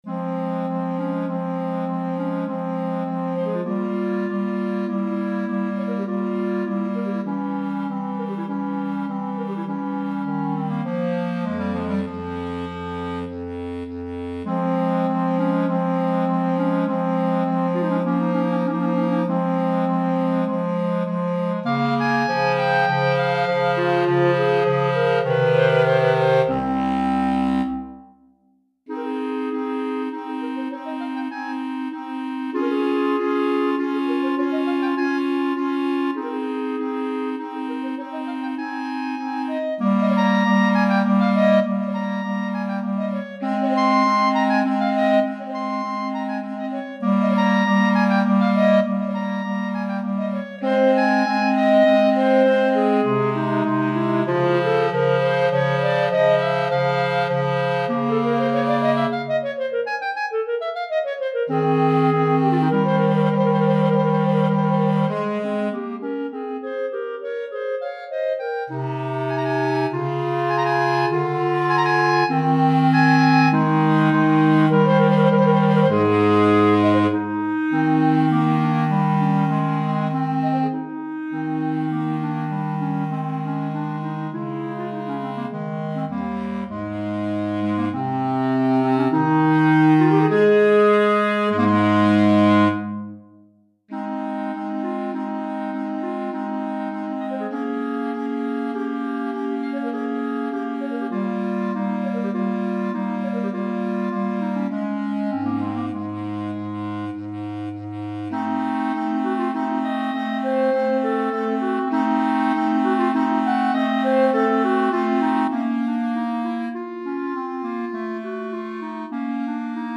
2 Clarinettes en Sib et Clarinette Basse